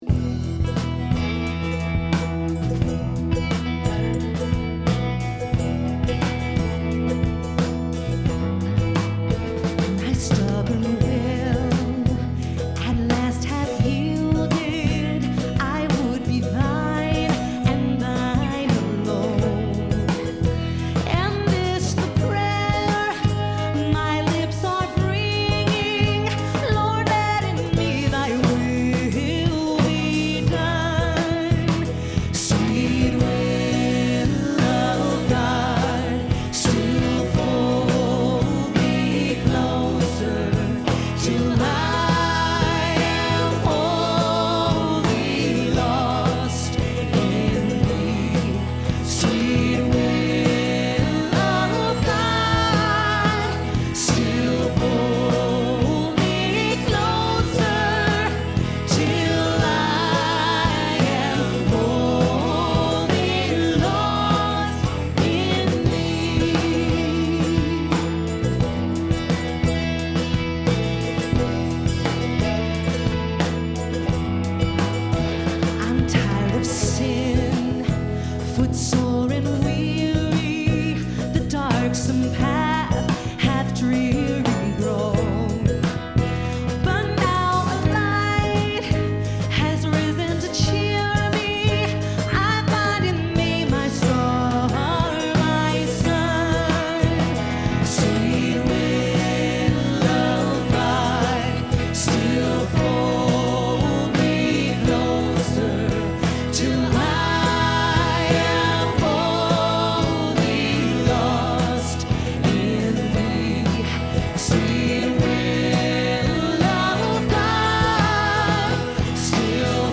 Wayfaring Stranger
Performed live at Terra Nova - Troy on 1/3/10.